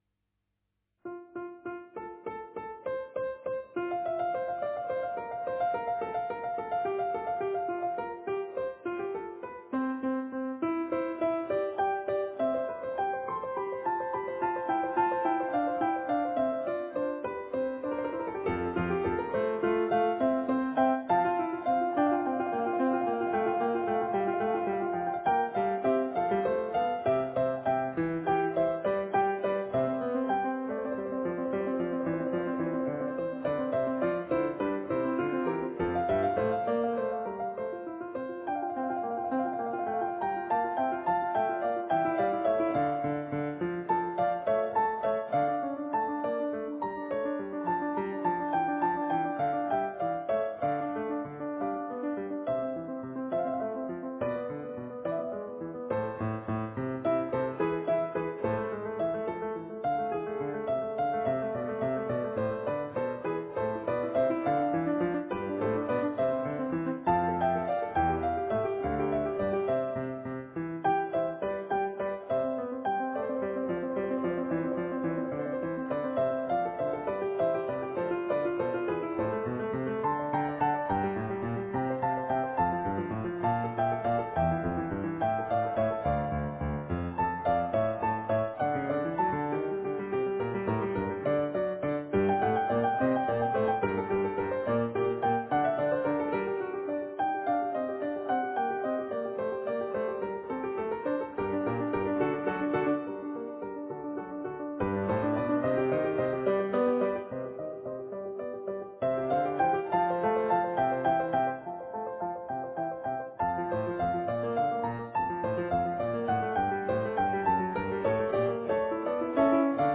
François Couperin: gli Ordres eseguiti al pianoforte - mp3
Registrazioni di musica classica.